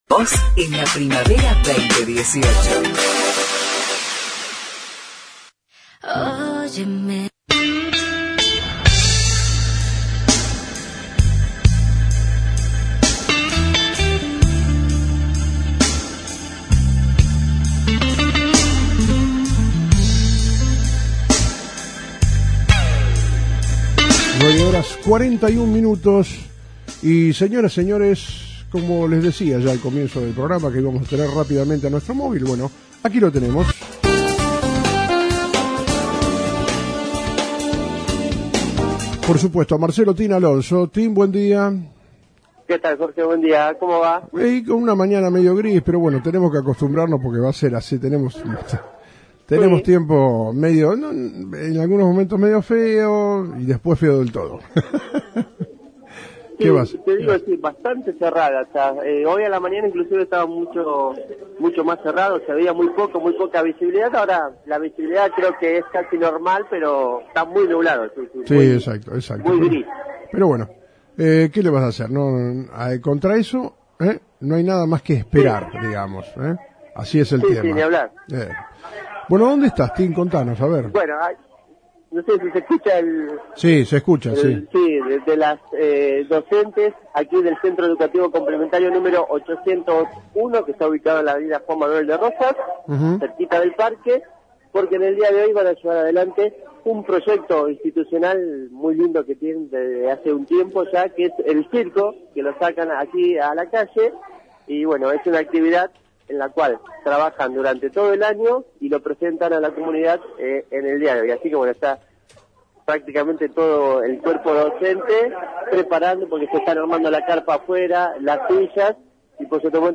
Docentes e integrantes del mismo